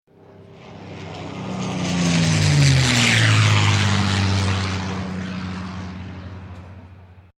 乗り物
セスナ（116KB）